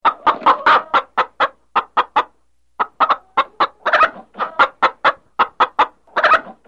Sound Effects Hen